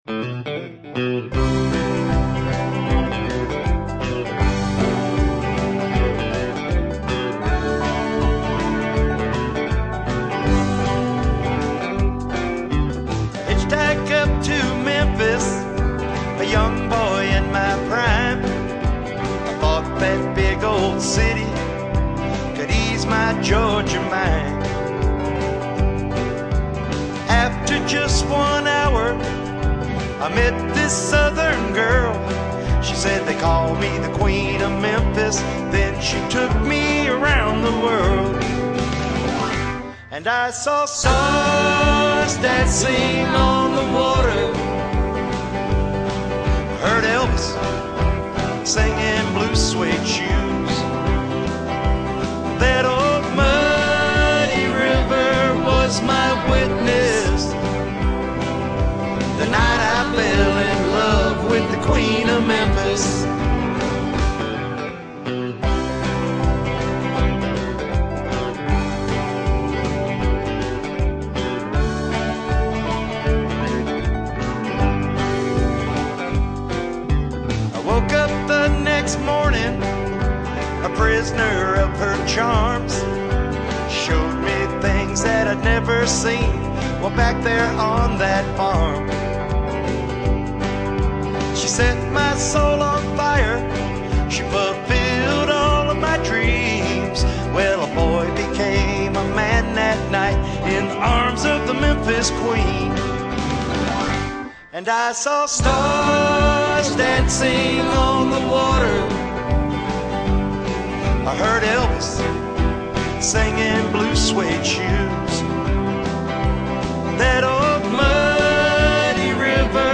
Steel Guitar
Fiddle / Keyboard / Backing Vocals
Drums / Backing Vocals